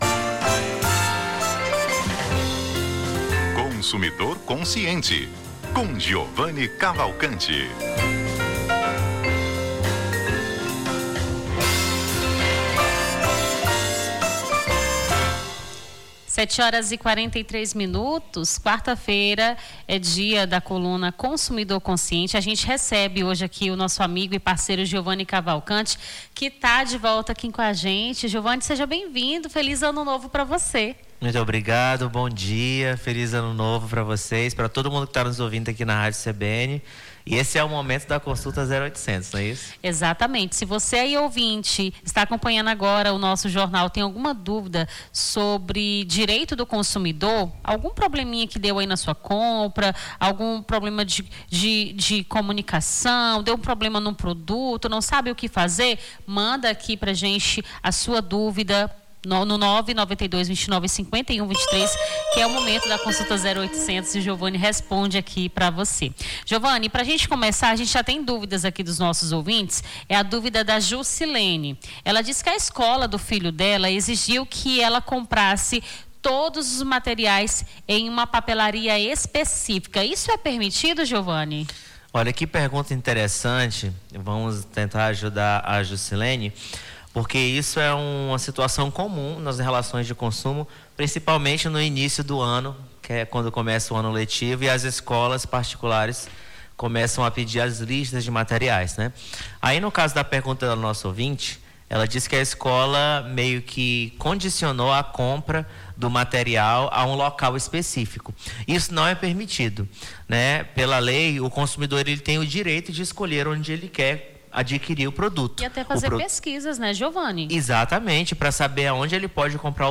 Consumidor Consciente: advogado esclarece dúvidas sobre direito do consumidor